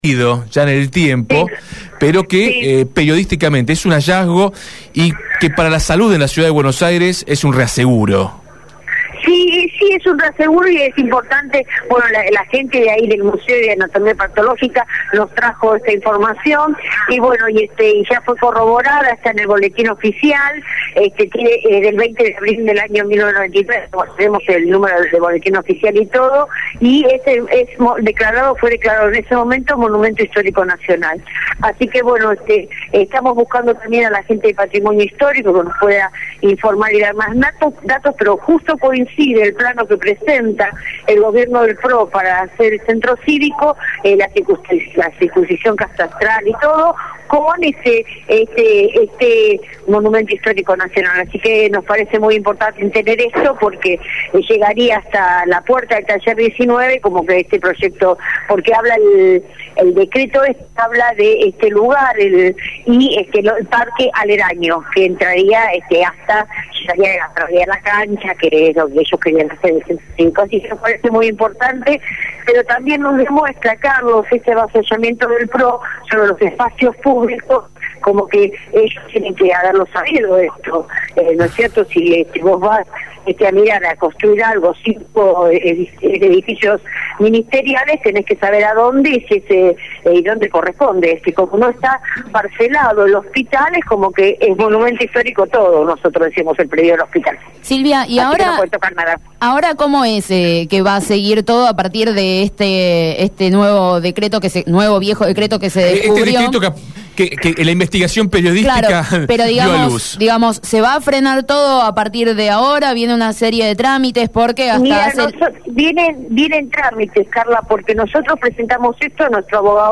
En comunicación telefónica con Radio Gráfica